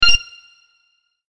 ボタン・システム （87件）